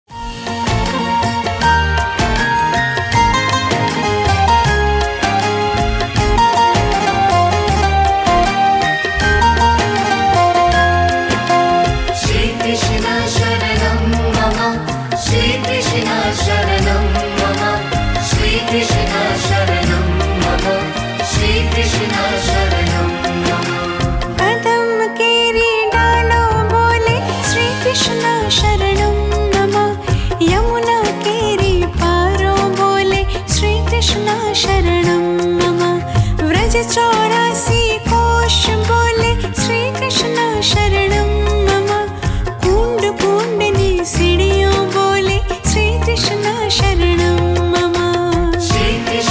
PopularKrishnaBhajan_ShriKrishnaSharanamMamah(श्रीकृष्णशरणमममः)VeryBeautifulSong.mp3